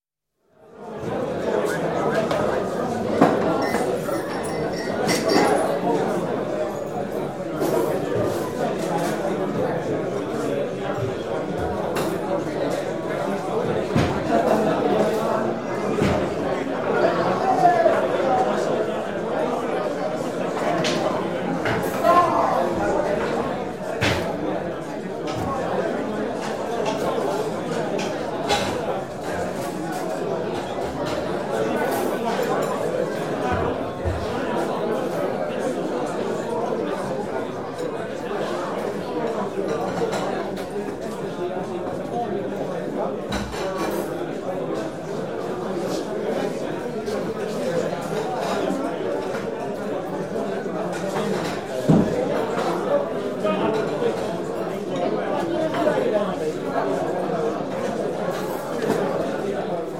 bbc_rewind_crowds_60s.mp3